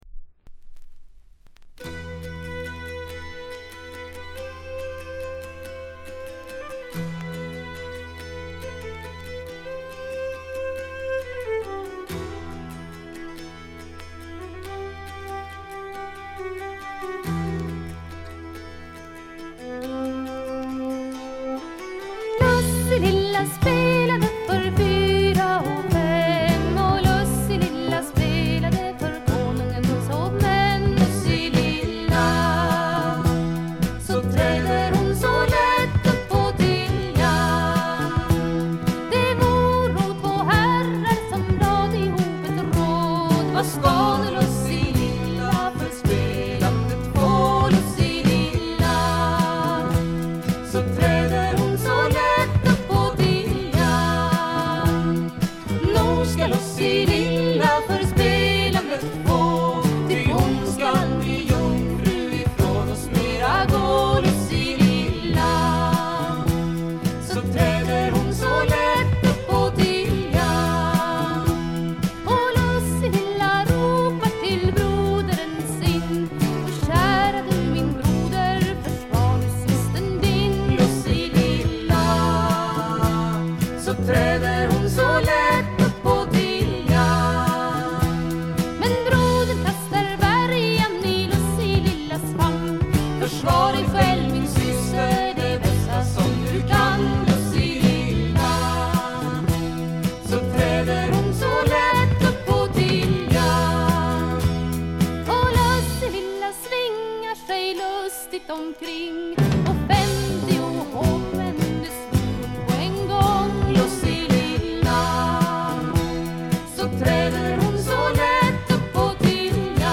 B1エンディングからB2冒頭にかけて軽微な周回ノイズ。
スウェーデンのトラッド・フォーク・グループ。
ずばりスウェーデン産トラッド・フォークの名盤にしてフィメール・フォークの名盤でもあります。
重厚でどっしりとしたバンド・サウンドが味わえます。
試聴曲は現品からの取り込み音源です。
Vocals, Violin, Hardingfele, Percussion
Accordion, Oboe
Acoustic Bass